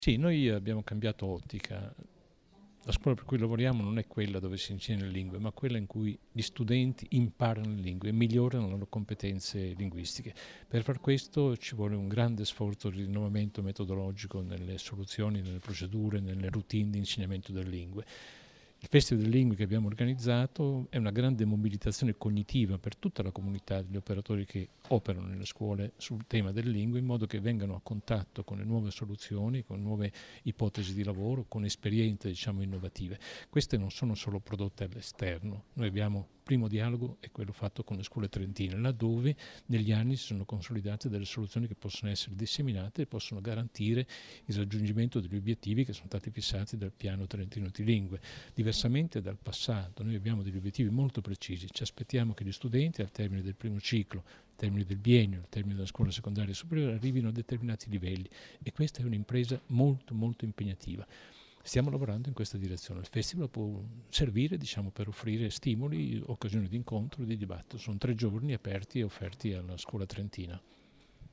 Audio intervista